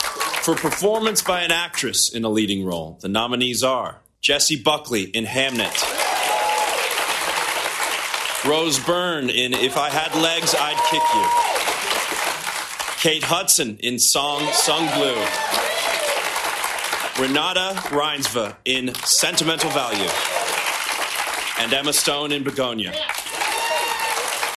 The nominations were announced by actors Danielle Brooks and Lewis Pullman in Beverly Hills via a livestream…………